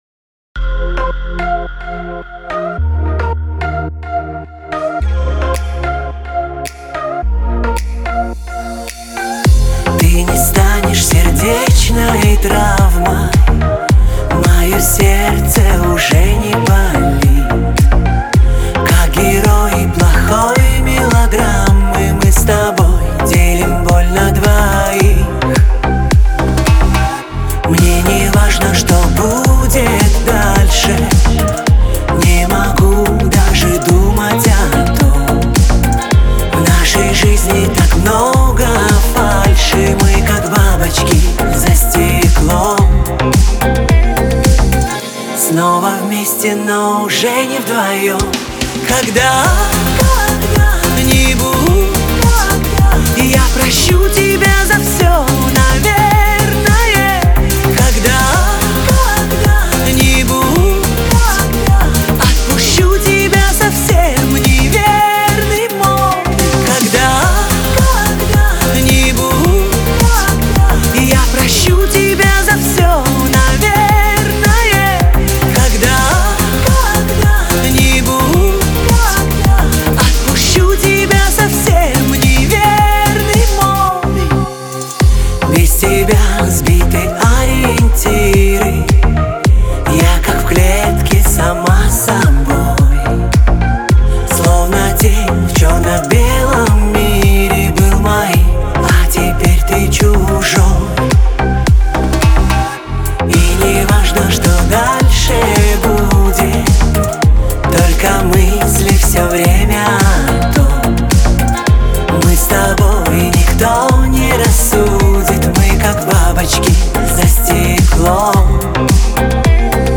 выразительный вокал и атмосферное звучание